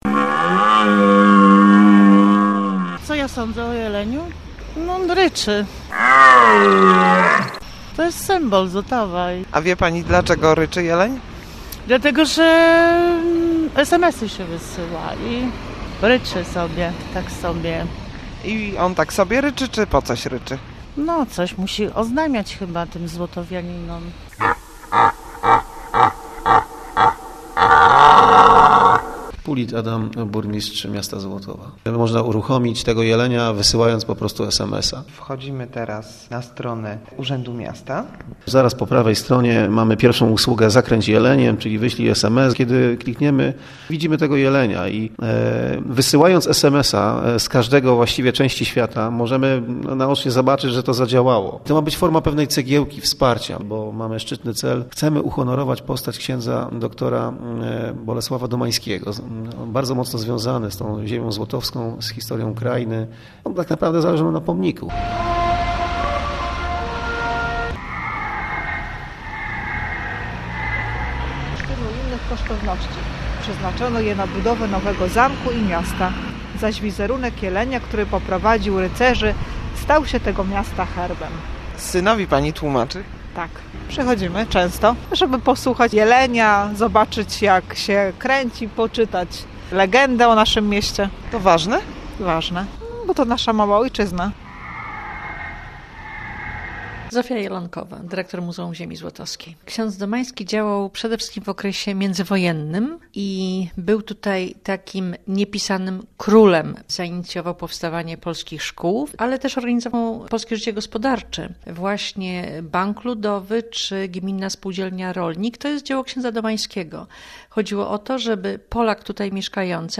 W samo południe jeleń obraca się wokół własnej osi i ryczy. Towarzyszą temu fanfary i złotowski hejnał.
95s9dof6bkmxc4b_kiedy-ryczy-jelen-ze-zlotowa.mp3